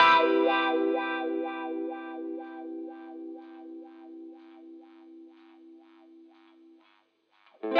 08 Wah Guitar PT1.wav